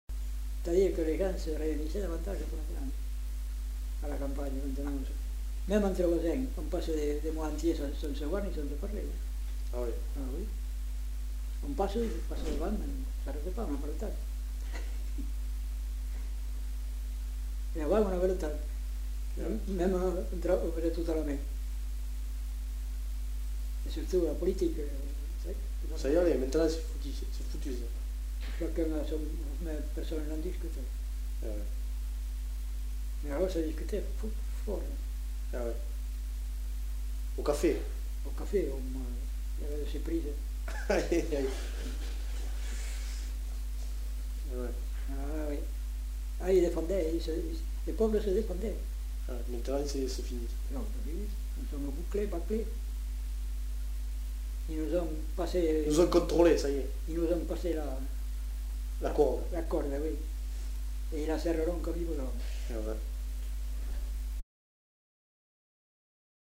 Lieu : Garganvillar
Genre : témoignage thématique